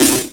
Snare Drum 67-02.wav